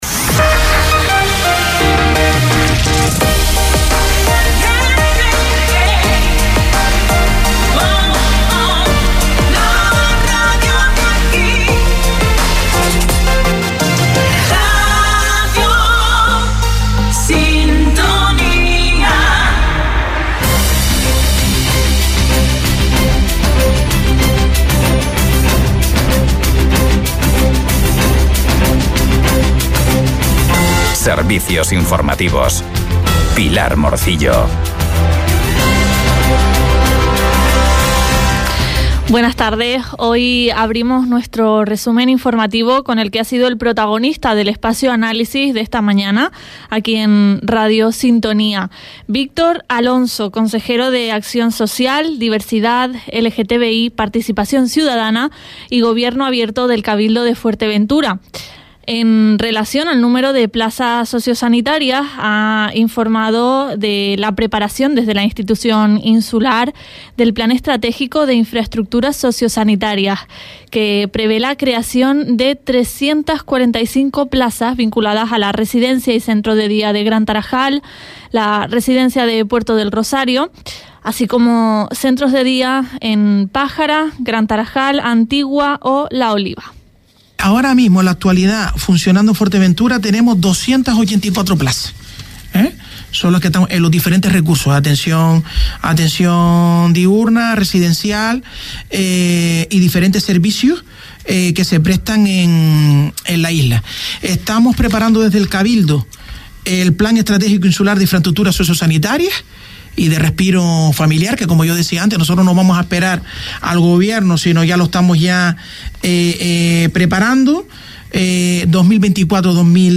Informativos en Radio Sintonía – 04.03.24